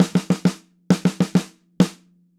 Snare Drum Fill 03.wav